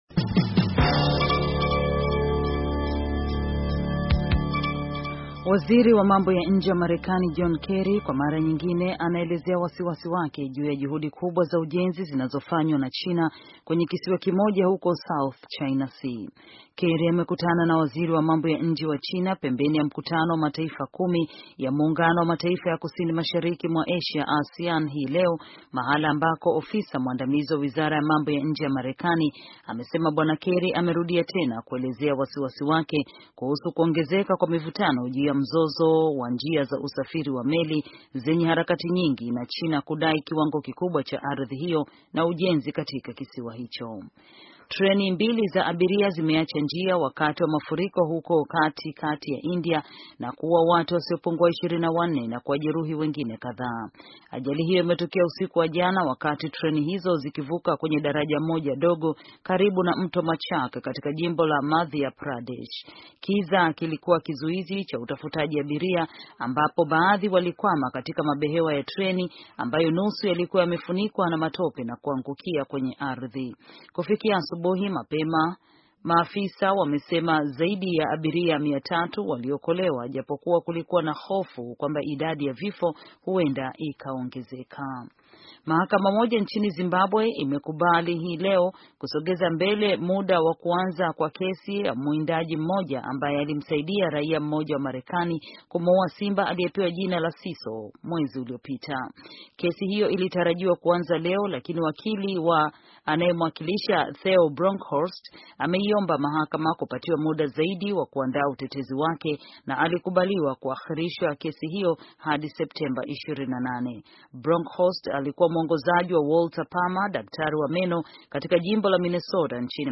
Taarifa ya habari - 4:20